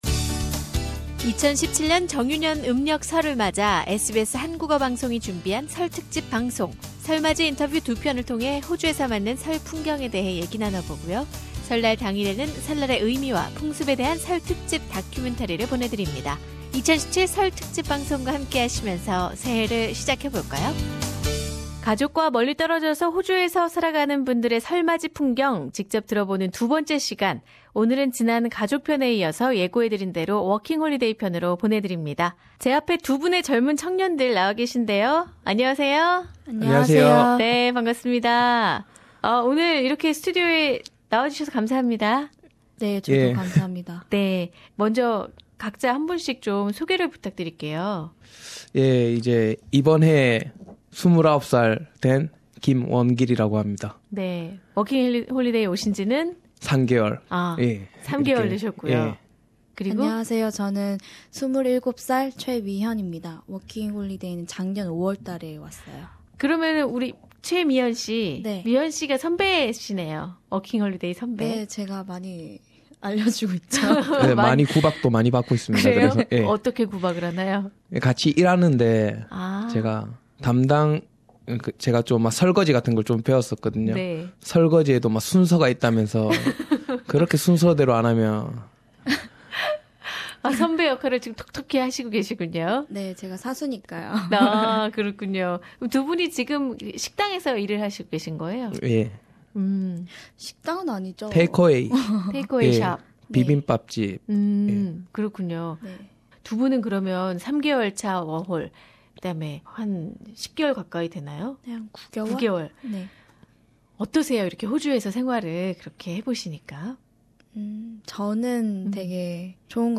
[설특집 인터뷰] 호주에서 맞는 설맞이 풍경 2부
SBS 설특집 기획 두번째 편. 가족과 멀리 떨어져 호주에서 지내는 워킹홀리데이 청년들이 한국의 부모님께 새해인사를 전합니다.